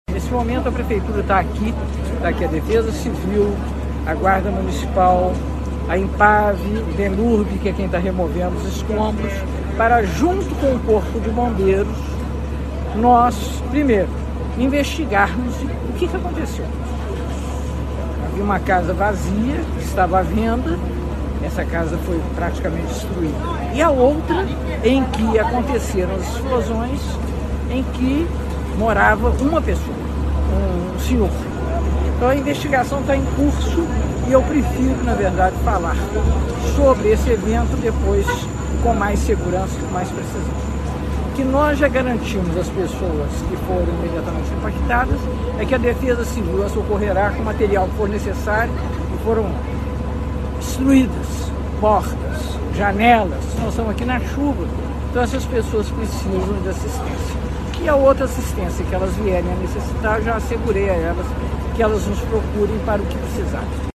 Ouça a prefeita Margarida Salomão